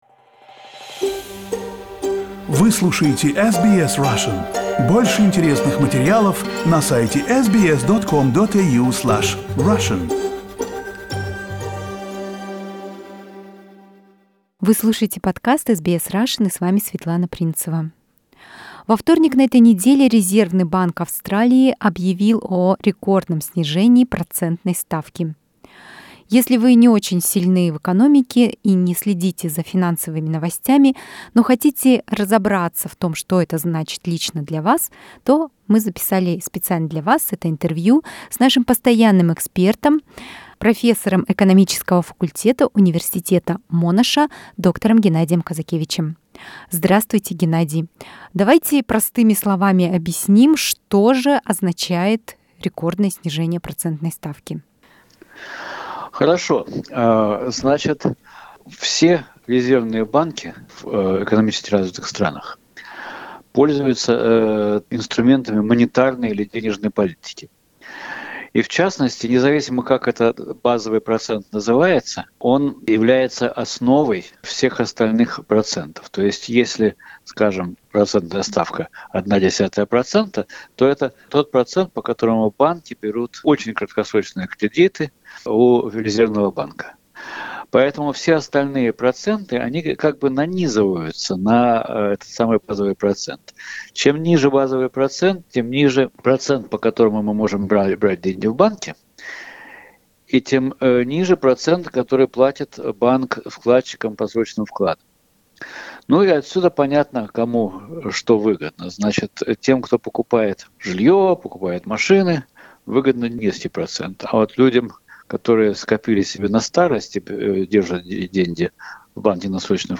Sorry, this interview is available in Russian only.